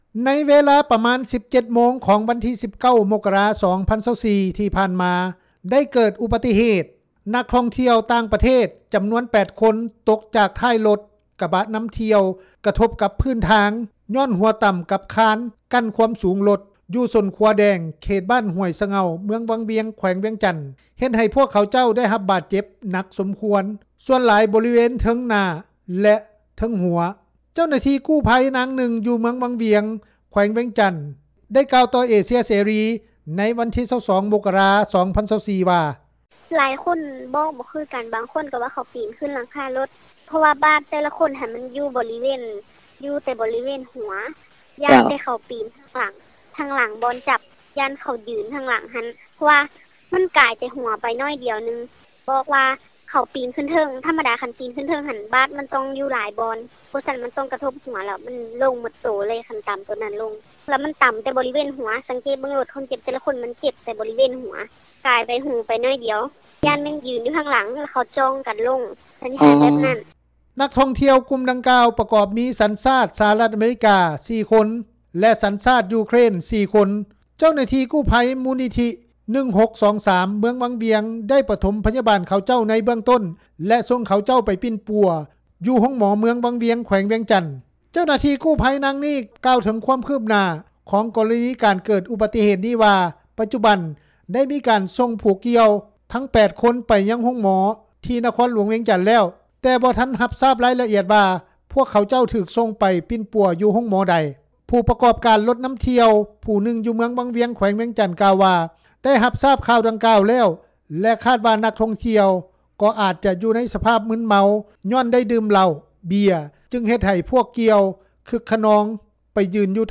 ດັ່ງເຈົ້າໜ້າທີ່ກູ້ໄພ ນາງນຶ່ງ ຢູ່ເມືອງວັງວຽງ ແຂວງວຽງຈັນ ກ່າວຕໍ່ວິທຍຸ ເອເຊັຽເສຣີ ໃນວັນທີ 22 ມົກກະຣາ 2024 ນີ້ວ່າ:
ດັ່ງທີ່ຍານາງ ກ່າວຕໍ່ວິທຍຸເອເຊັຽເສຣີ ໃນມື້ດຽວກັນນີ້ວ່າ: